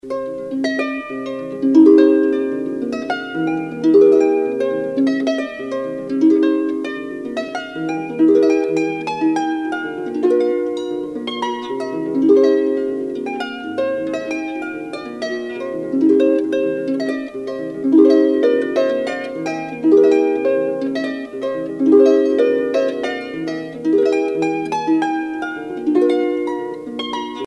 Pièce musicale éditée